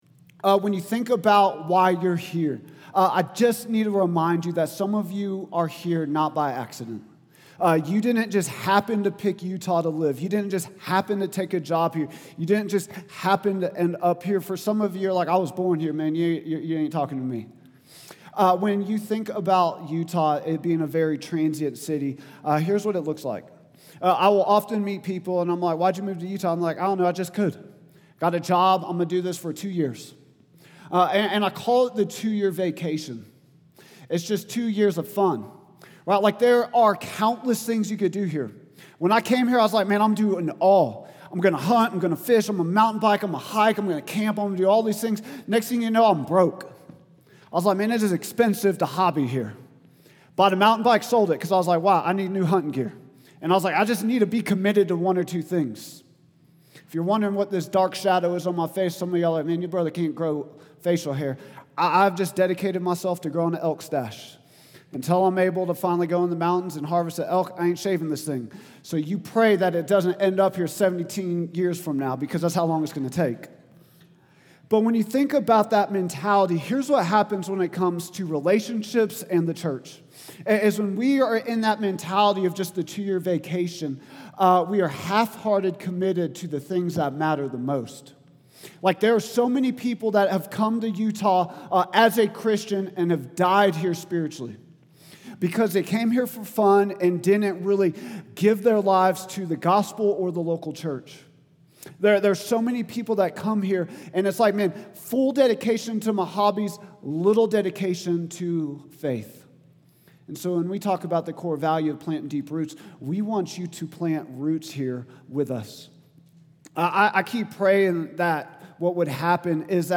Vision & Values Meet Our Team Statement of Faith Sermons Contact Us Give Start of a Movement | Week 6 June 1, 2025